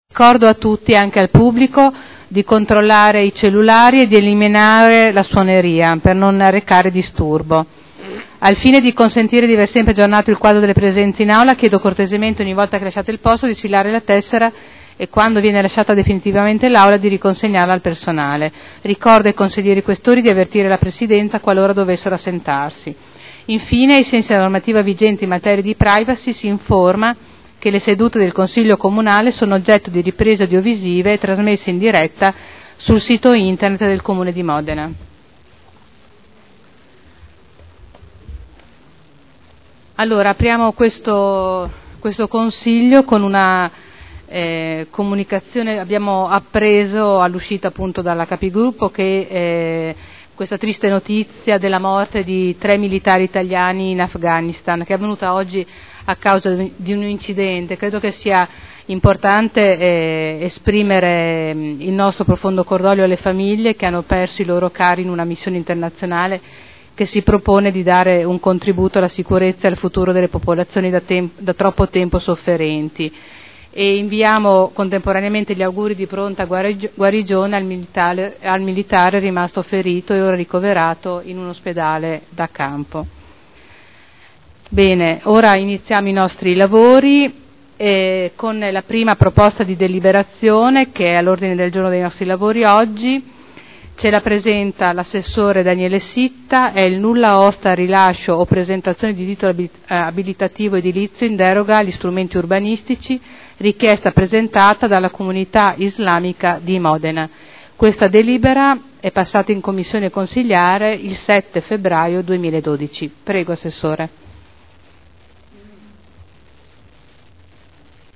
Il Presidente Caterina Liotti apre il Consiglio. Esprime cordoglio per la morte dei soldati italiani in Afghanistan.